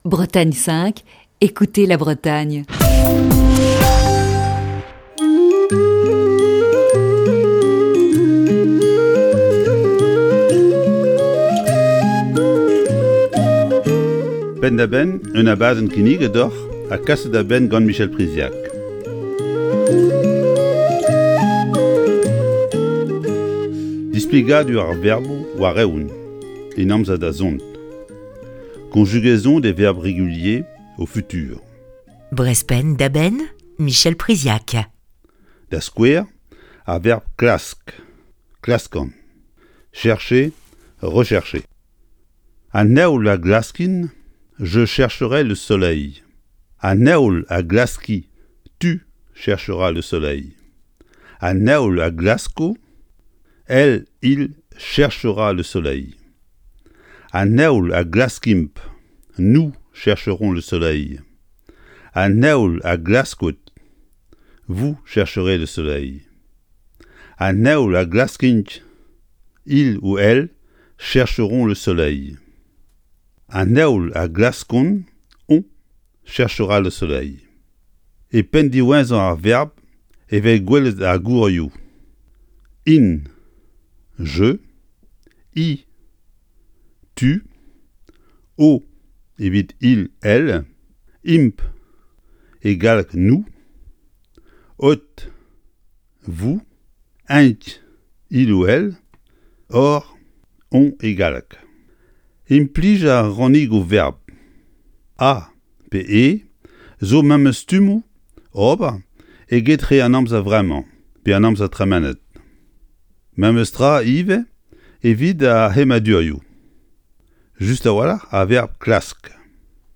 Chronique du 8 avril 2021.